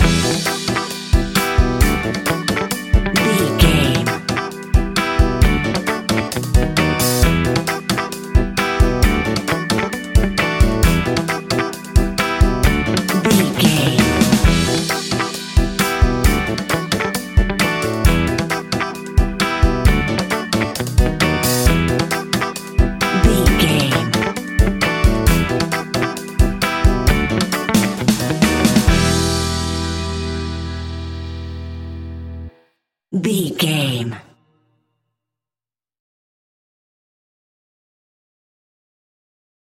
A groovy piece of upbeat Ska Reggae!
Aeolian/Minor
Fast
instrumentals
laid back
chilled
off beat
drums
skank guitar
hammond organ
percussion
horns